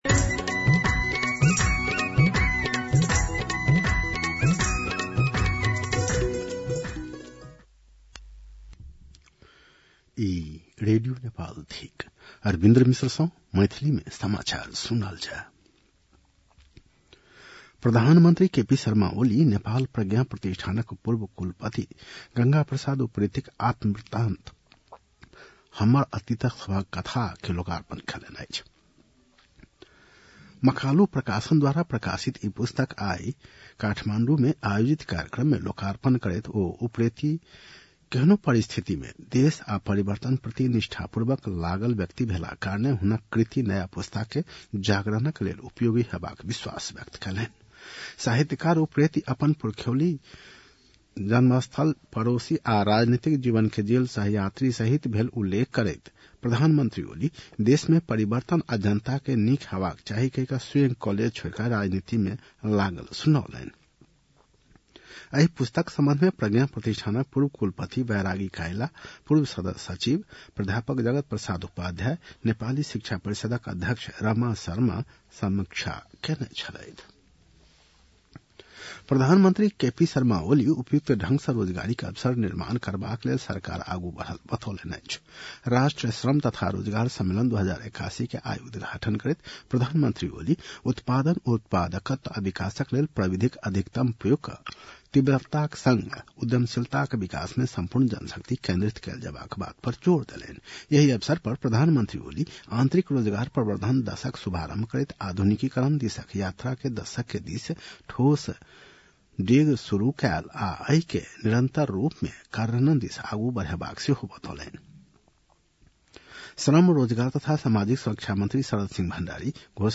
मैथिली भाषामा समाचार : २७ फागुन , २०८१